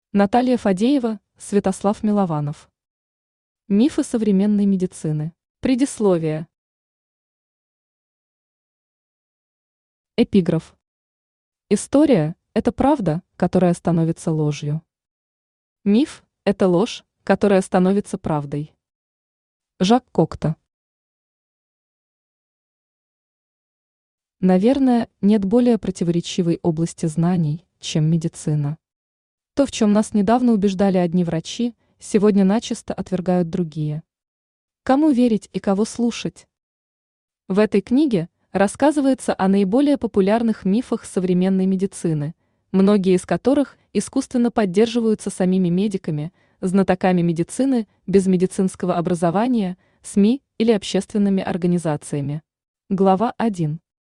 Аудиокнига Мифы современной медицины | Библиотека аудиокниг
Aудиокнига Мифы современной медицины Автор Наталья Ивановна Фадеева Читает аудиокнигу Авточтец ЛитРес.